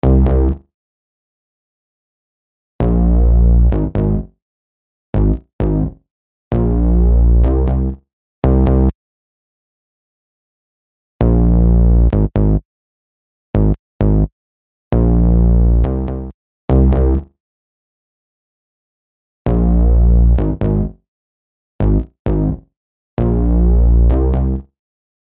H910 Harmonizer | Synth Bass | Preset: Boogie Honey Bass
H910-Harmonizer-Eventide-Synth-Bass-Boogie-Honey-Bass.mp3